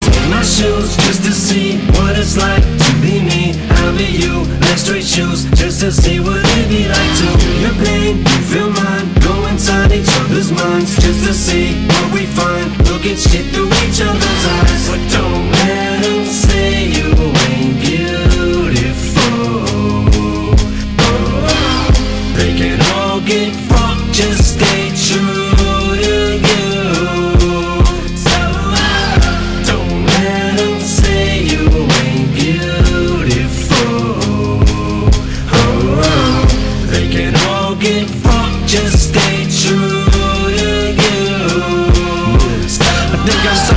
• Качество: 192, Stereo
мужской вокал
Хип-хоп
спокойные
Rap
лирические